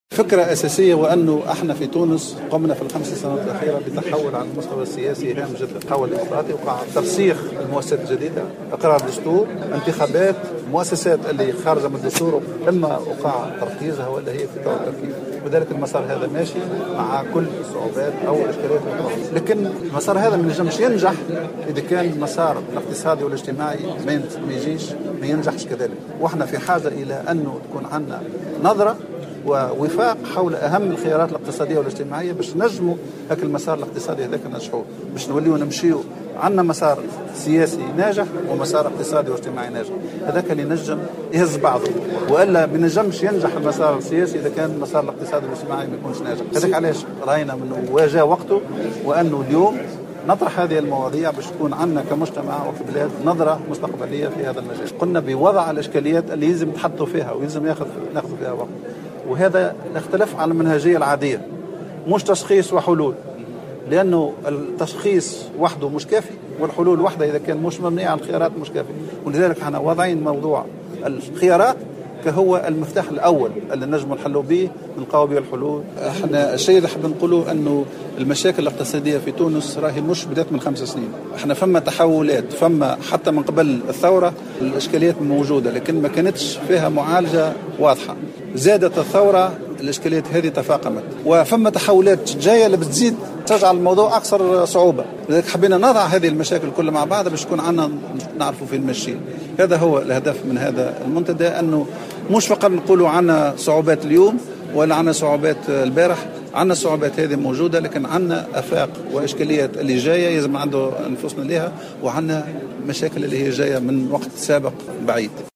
وتحدث محافظ البنك المركزي السابق، مصطفى كمال النابلي، لمراسلنا حول الفكرة الأساسية من وراء تنظيم ندوة حول مسار اقتصادي موحد في البلاد، حيث أكد على ارتباط نجاح التحول الديمقراطي بنجاح المسار الاقتصادي والاجتماعي، وهو ما يستدعى وجود نظرة موحدة حول الخيارات الاقتصادية والاجتماعية.